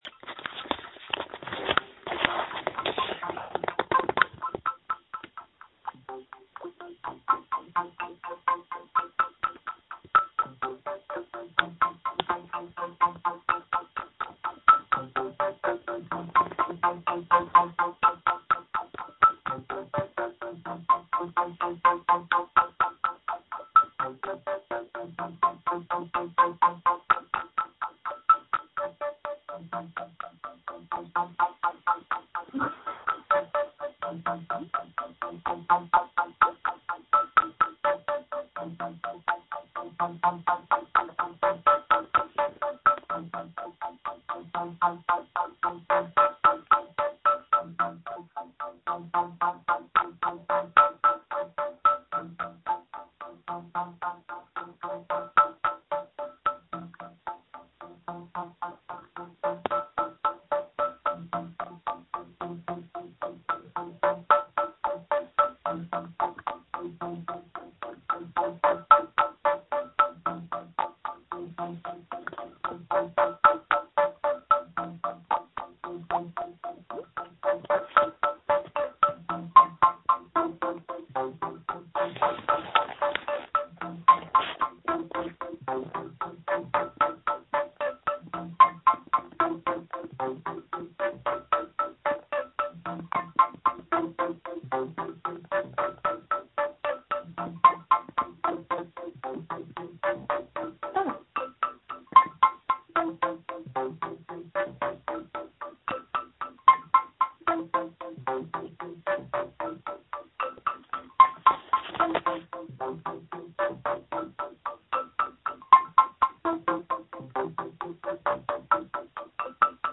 Electroplankton -> Monotron -> Overdriven Headphones -> Telephone